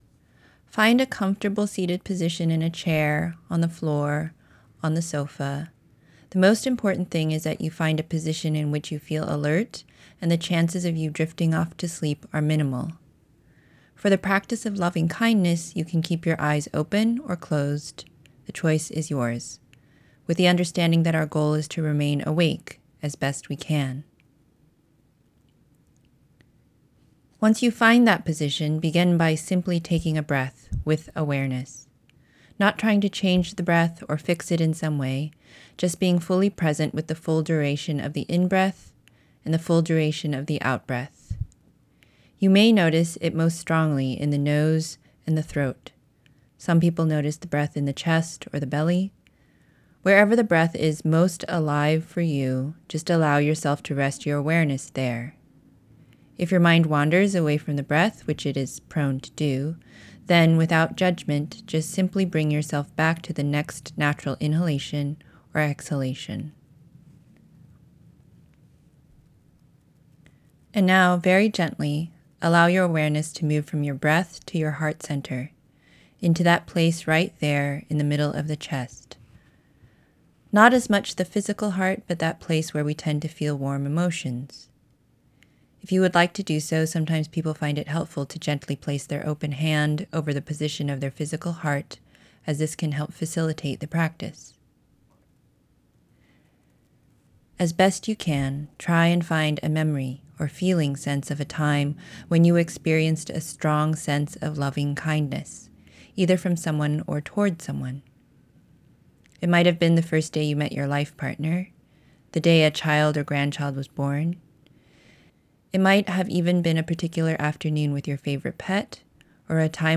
Stressed Out? Try This Loving Kindness Meditation
Loving-Kindness-Meditation.wav